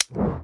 Scroll_Stop_Sound_normal.mp3